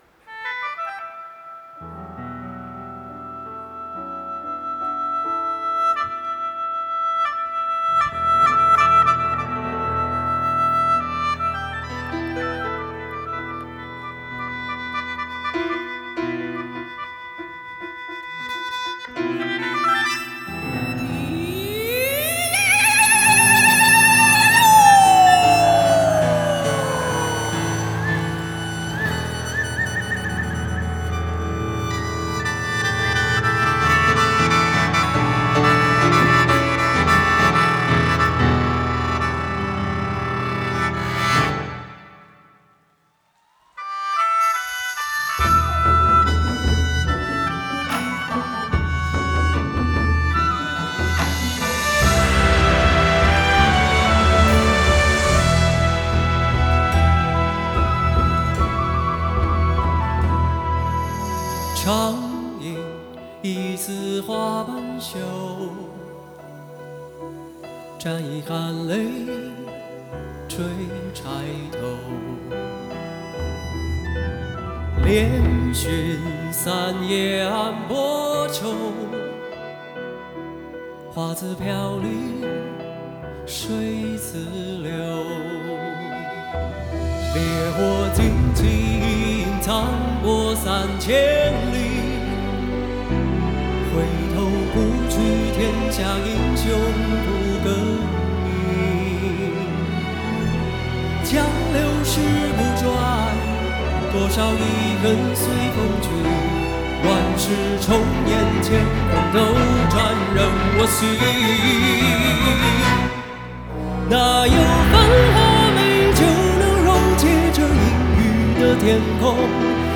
钢琴
笙